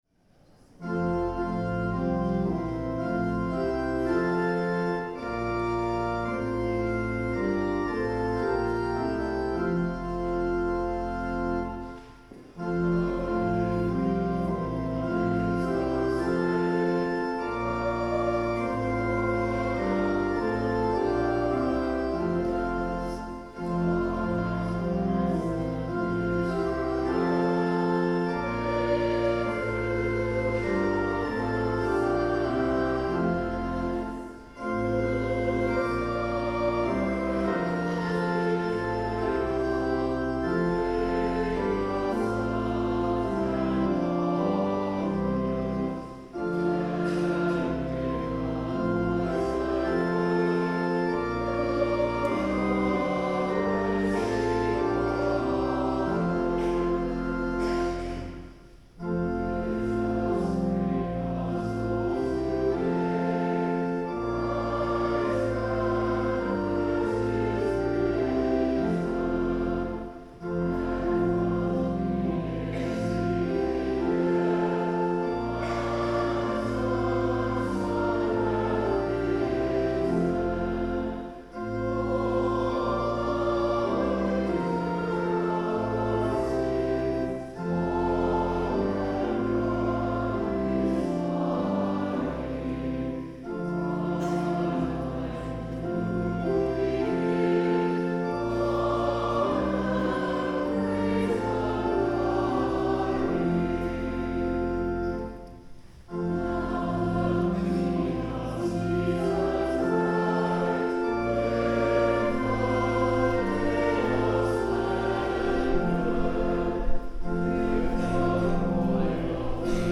Second Reading: Revelation 1:4-8 – Reading in French
The Lord’s Prayer (sung)